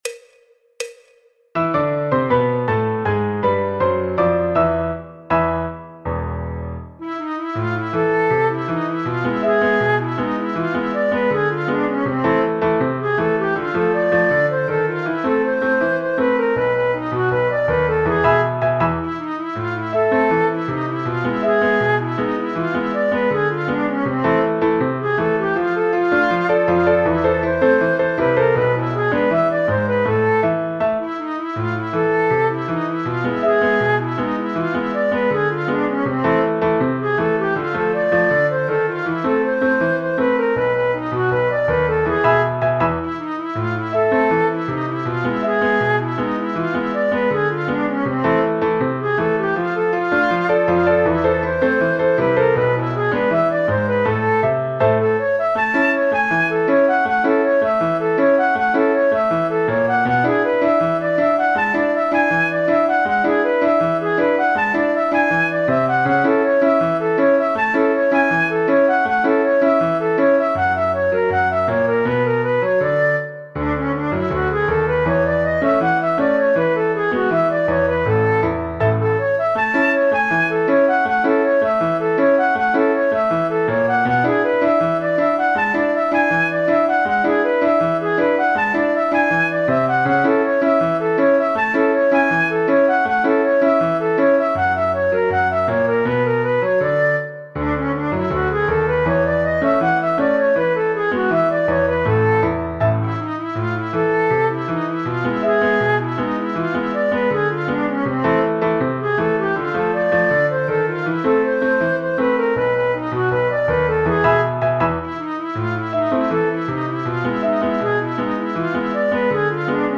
en La menor : La Mayor
Choro, Jazz, Popular/Tradicional